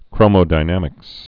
(krōmō-dī-nămĭks)